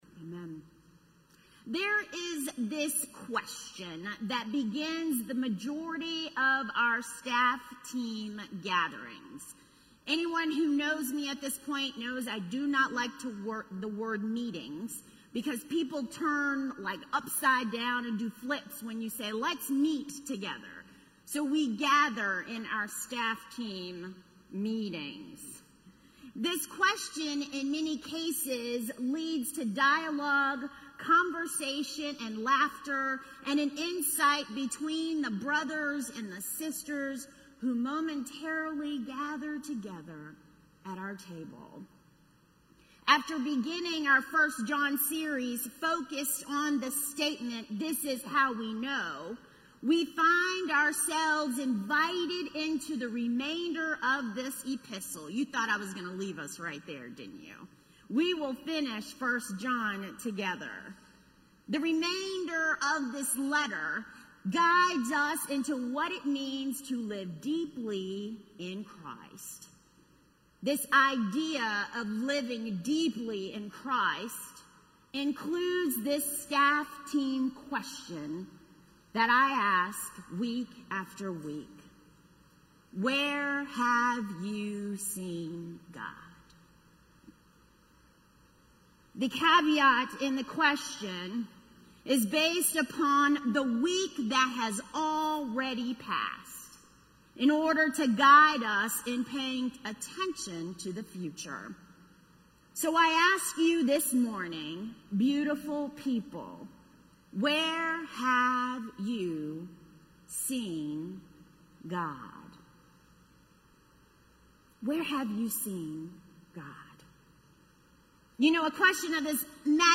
A message from the series "Living Deep in Christ."